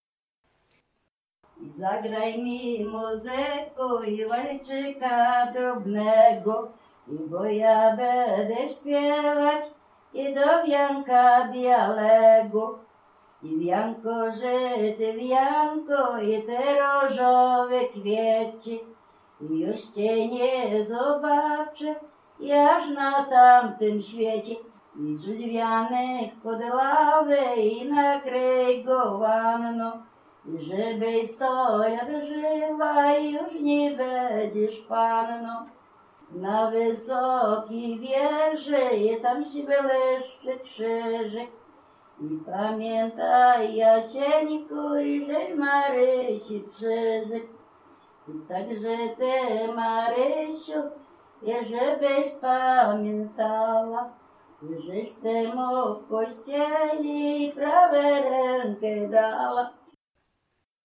W wymowie Ł wymawiane jako przedniojęzykowo-zębowe;
e (é) w końcu wyrazu zachowało jego dawną realizację jako i(y)
Przyśpiewki weselne
weselne wesele przyśpiewki oczepinowe